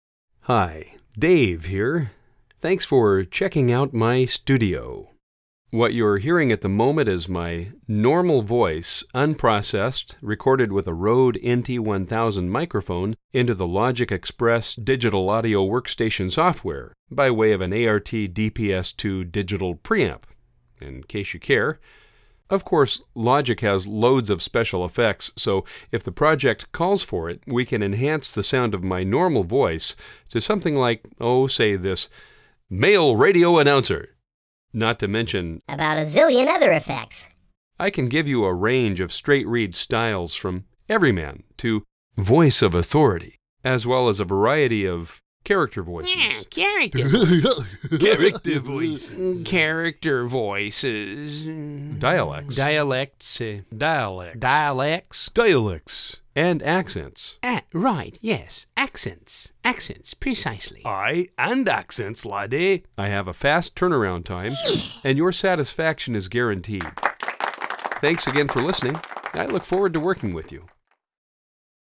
Male
Adult (30-50)
Warm, clear, articulate, natural sounding professional male voice, ranging from younger, middle-aged adult to more mature, older sound (30's, 40's, and 50's) for voiceover, announcer/announcing, narration (narrator) for e-learning, slideshows, corporate, industrial, marketing & web videos (welcome, explainer), phone system (on-hold, IVR), etc.
Natural Speak
Studio Quality Sample